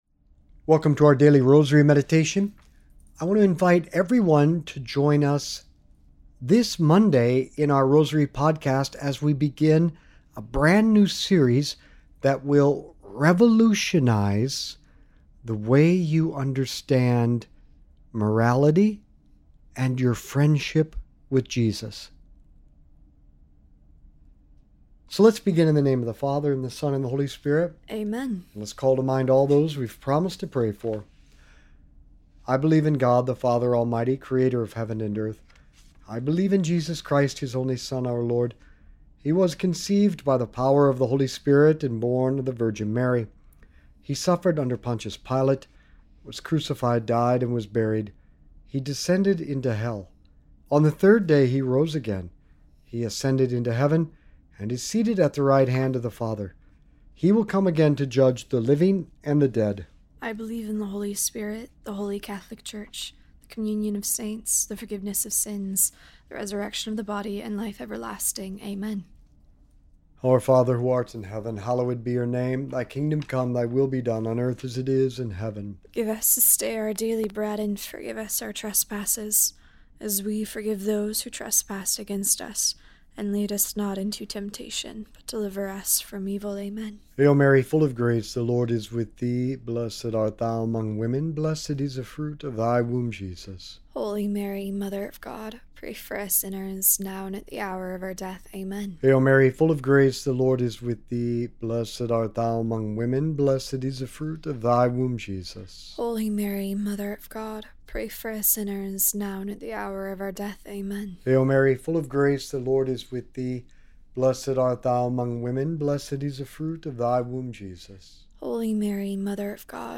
This episode presents a daily rosary meditation centered on prayer and sacrifice, featuring teachings from Saint Faustina about offering suffering for the conversion and salvation of souls. The hosts discuss how personal sacrifice, prayer, and suffering can be spiritually leveraged to help others fi